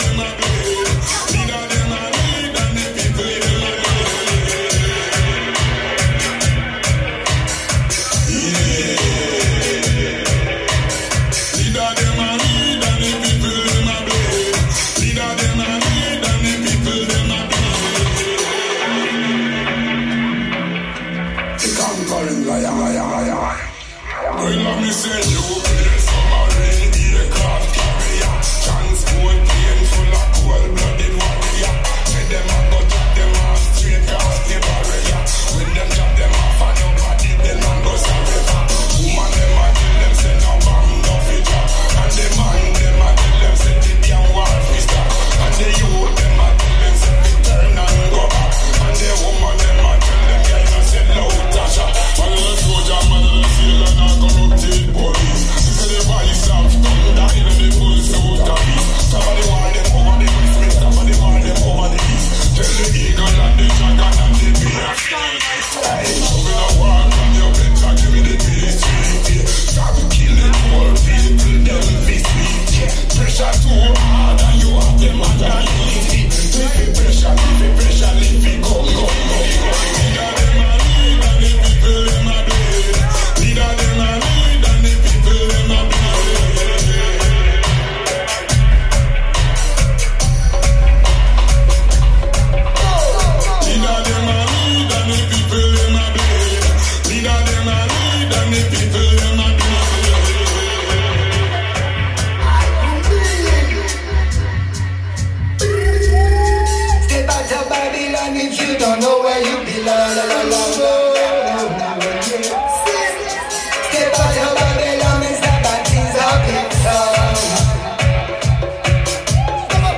equipment: studio projects c4 (cardioid) [xy] - > edirol r-44 [48 khz | 24 bit] < - studio projects c4 (omni) [a-b @ 50 cm]